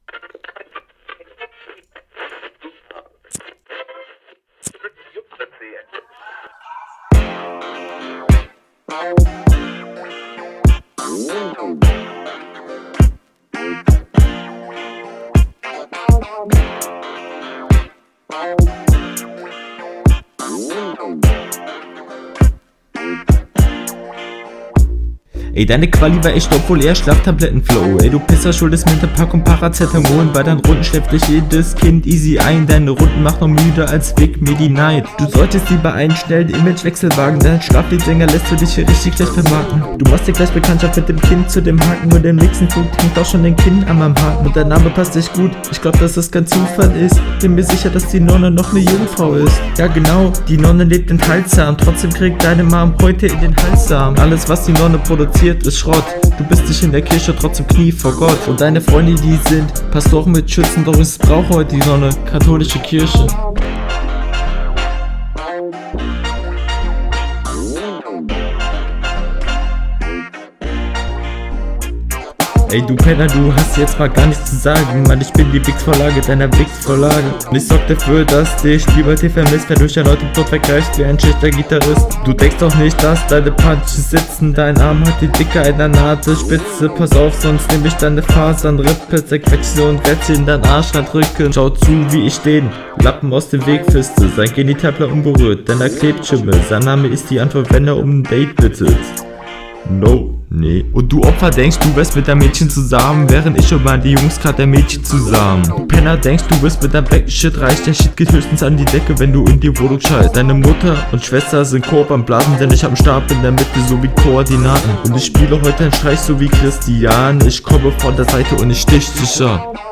Meiner Meinung ist die Runde an sich viel zu lang, die Beatswitches finde ich in …